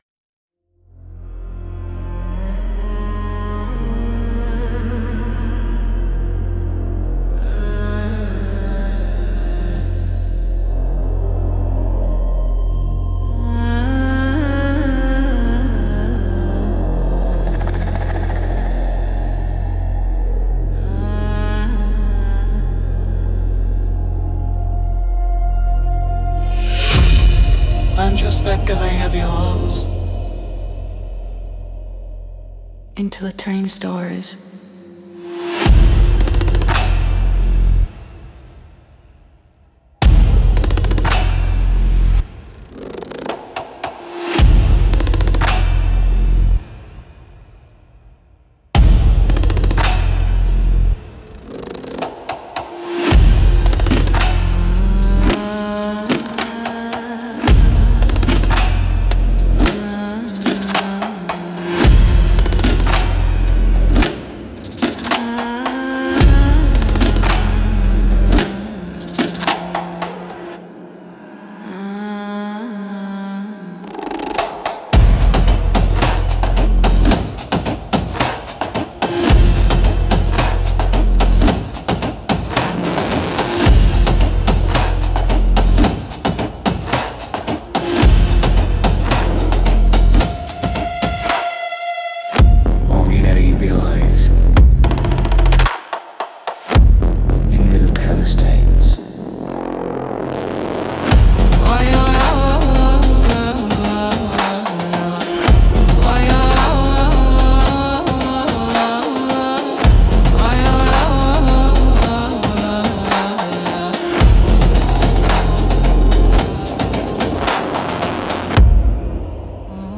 Instrumental - Whispers in the Smoke - 2.23